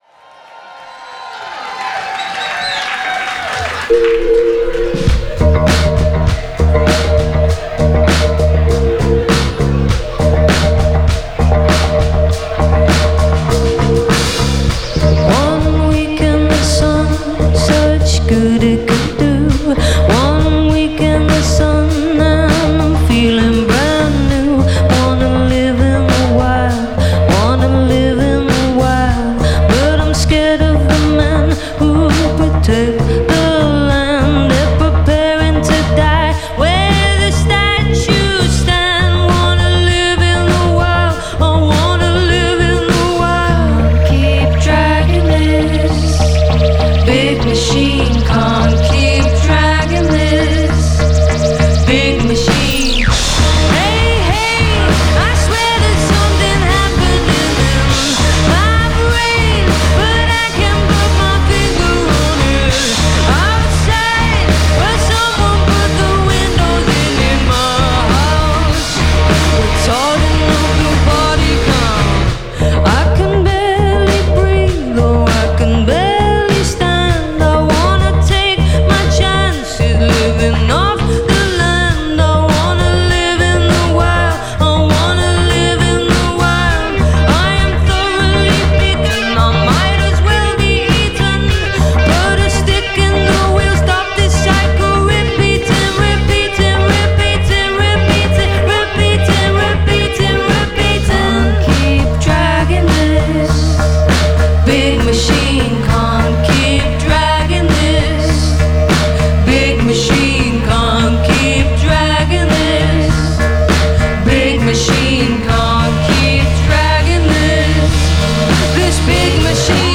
recorded live
Summer festival season is starting up.